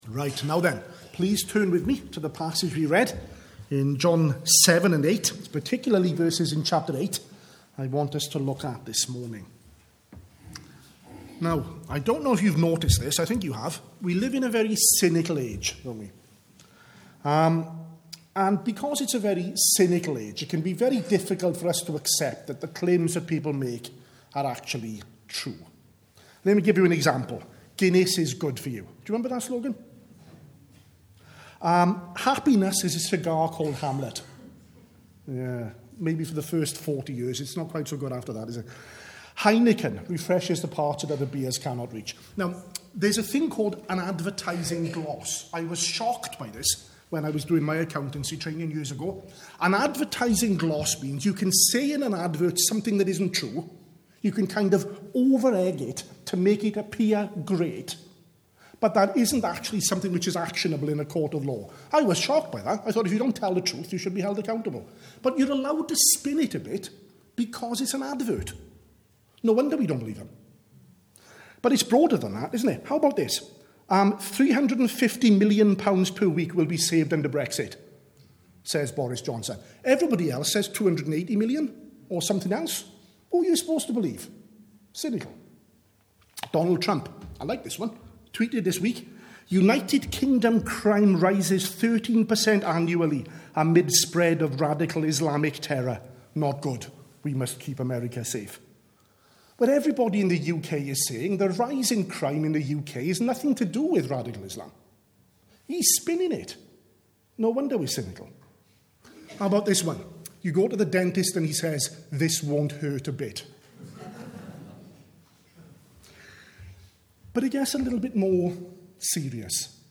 at the morning service